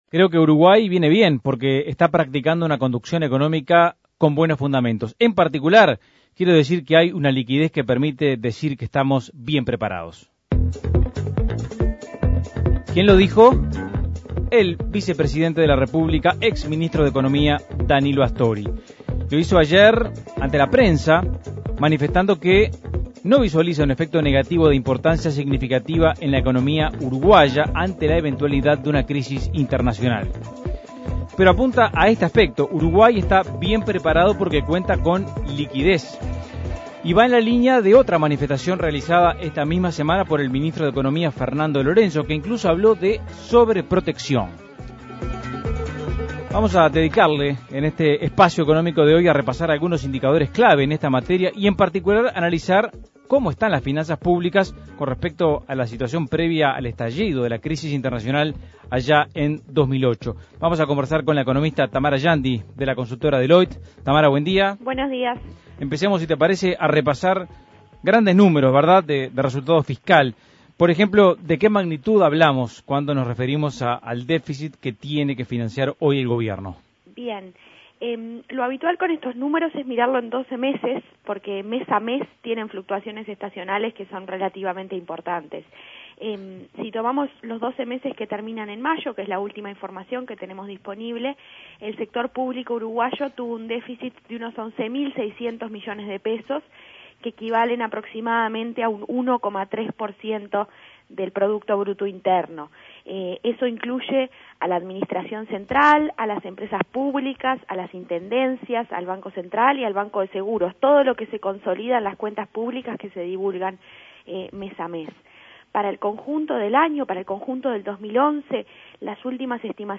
Análisis Económico ¿Cómo están las finanzas públicas para enfrentar un contexto externo más adverso?